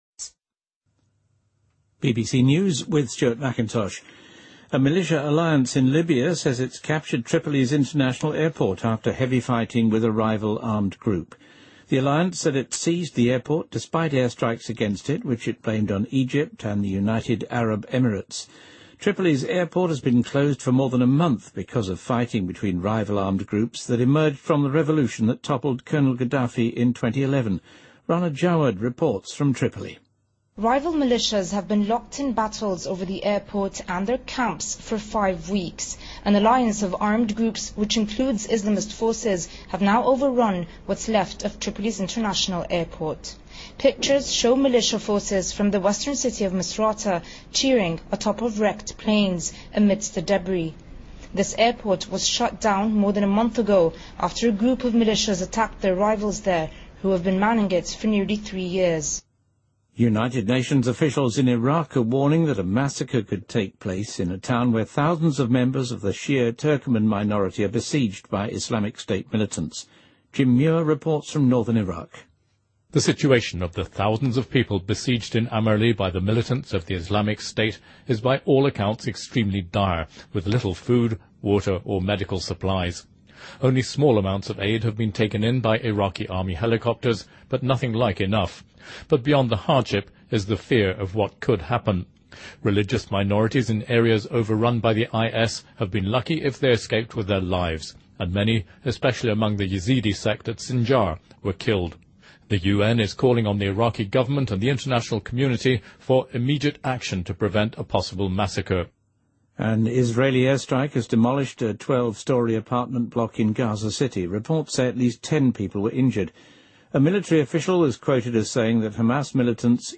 BBC News